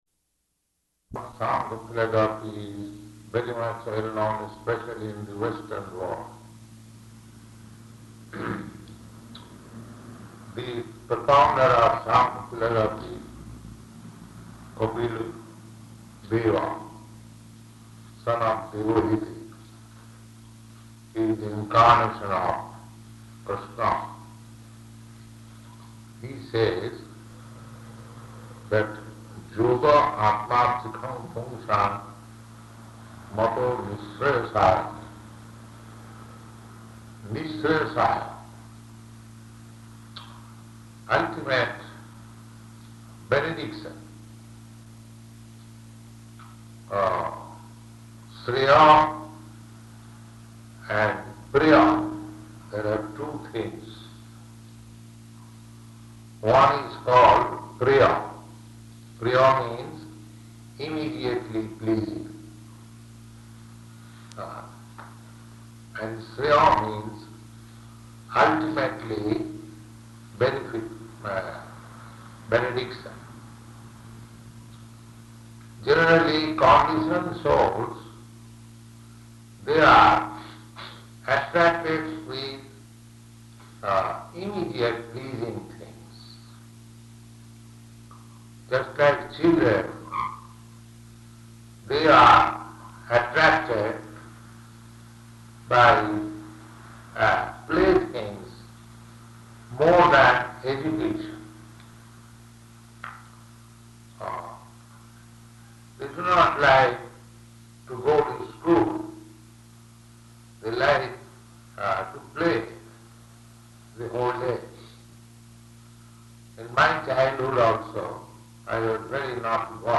Location: Los Angeles
[distorted audio]